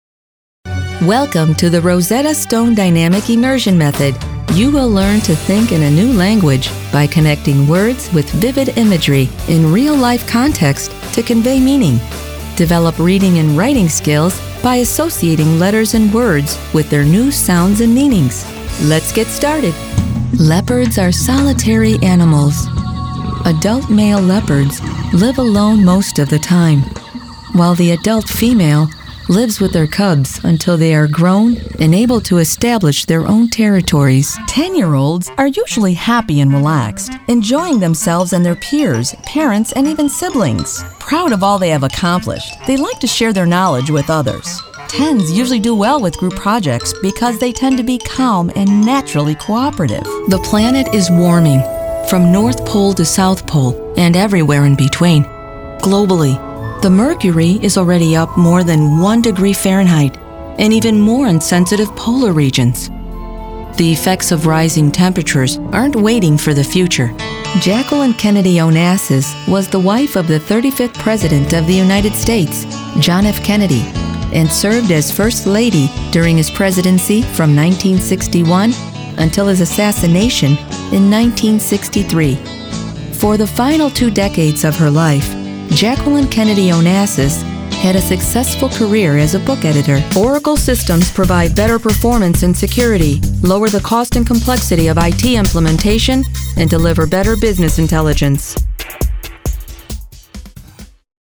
Her voice is natural, believable and engaging, perfect for a broad range of projects. Her voice is versatile, it can be friendly, fun, fresh, approachable, sincere, strong, intimate and sensual.
believable,honest,confident, warm,professional,smooth, sophisticated,fun,friendly, upbeat,trustworthy mom,sassy,sultry
middle west
Sprechprobe: Industrie (Muttersprache):